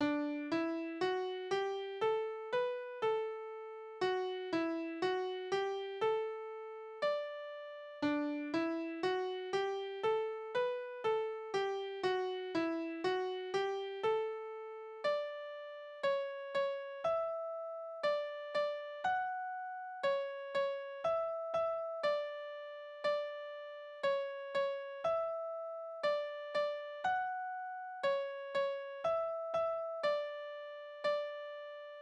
Tanzverse: Galopp
Tonart: D-Dur
Taktart: 4/4
Tonumfang: große Dezime
Besetzung: vokal
Kommentar Einsender*in: Bauerntanz 1850